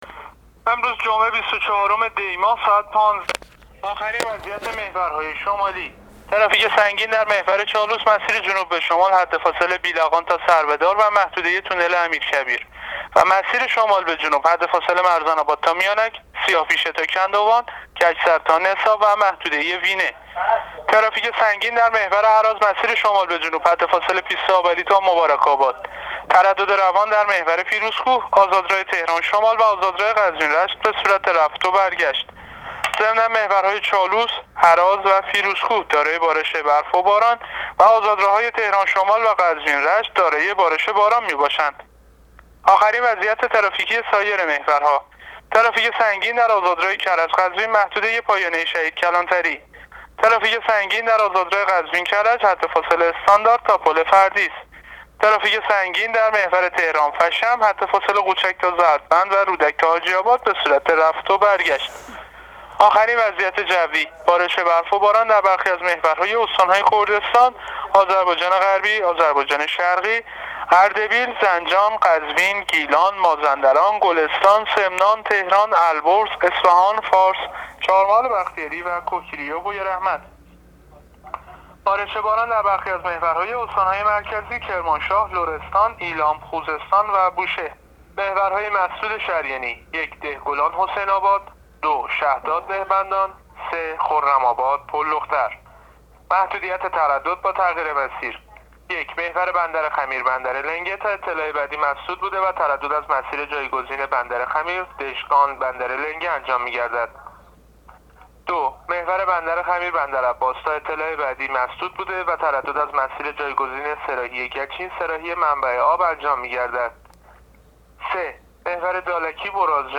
گزارش رادیو اینترنتی از آخرین وضعیت ترافیکی جاده‌ها تا ساعت ۱۵بیست‌وچهارم دی؛